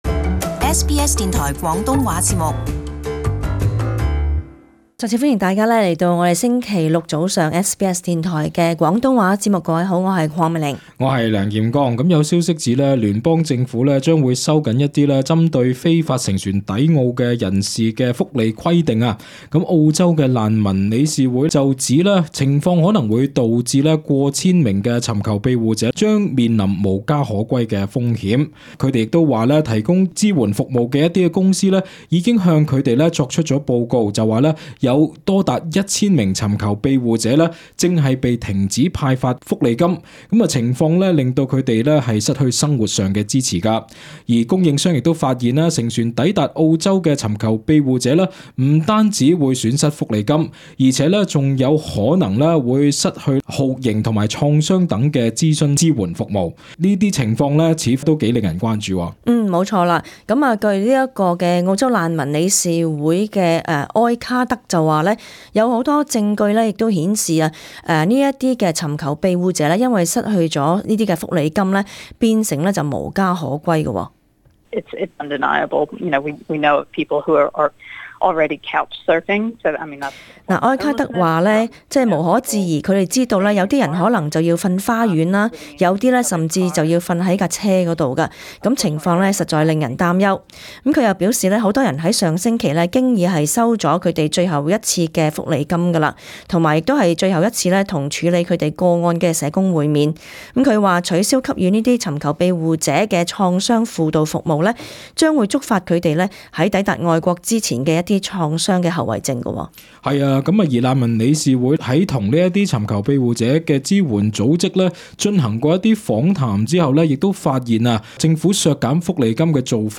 【時事報導】政府收緊褔利或致過千尋求庇護者露宿街頭